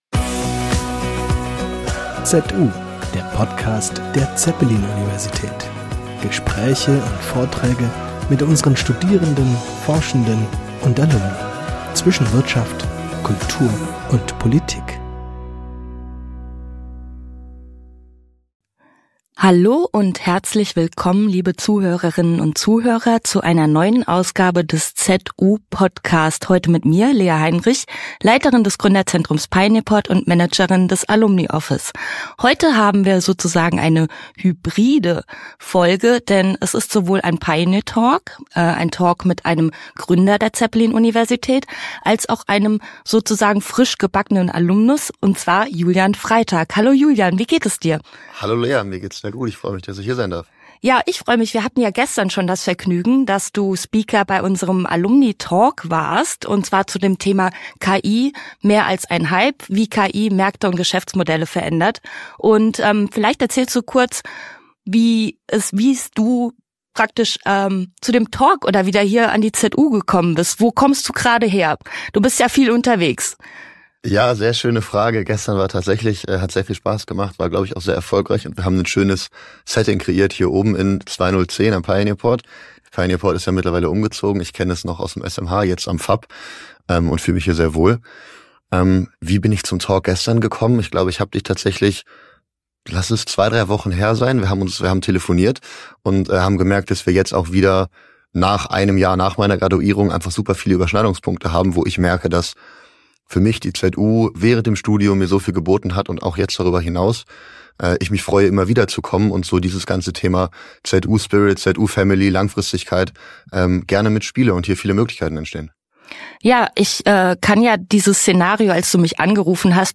Ein inspirierender Talk über Mut, Momentum und Macher-Mentalität.